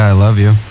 Radio show 2